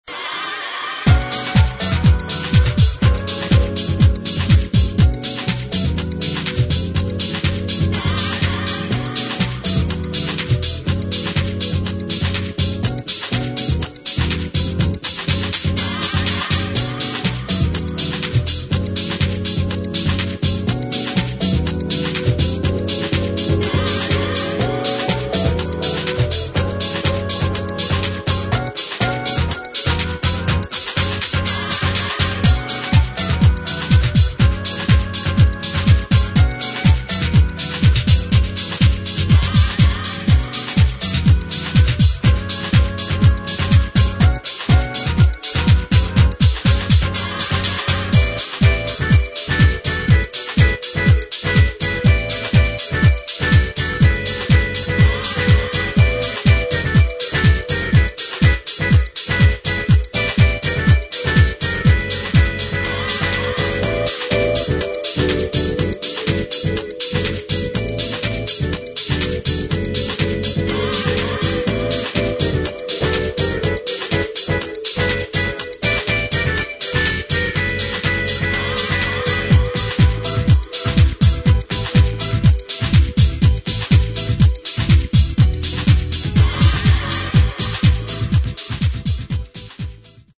[ DEEP HOUSE / NY HOUSE ]